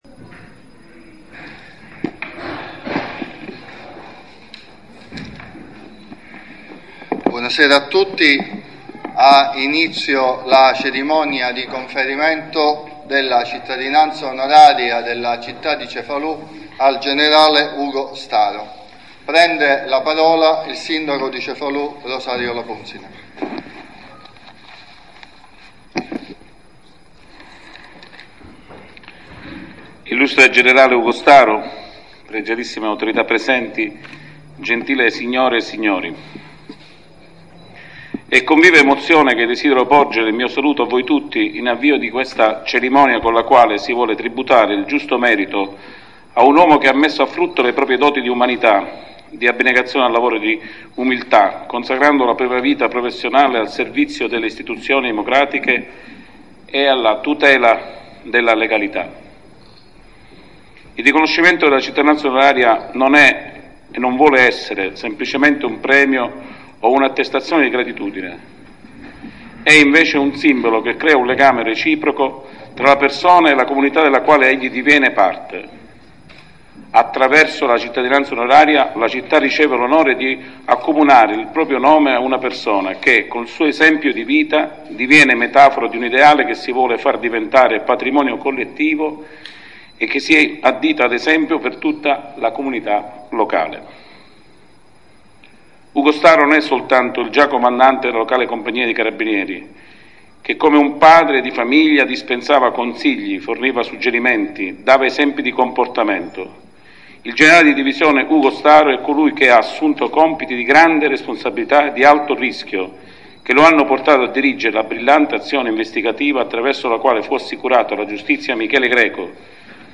00'00 Sindaco Rosario Lapunzina
30'00 Assessore Vincenzo Garbo, Lettura determina Sindacale